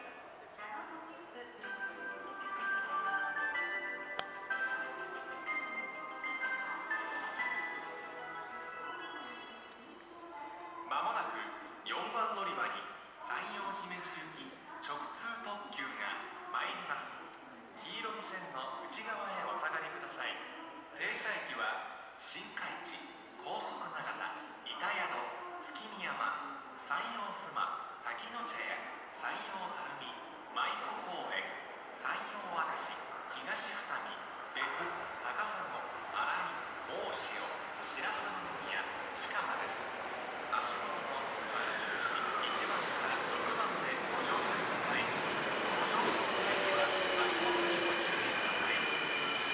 この駅では接近放送が設置されています。
接近放送直通特急　山陽姫路行き接近放送です。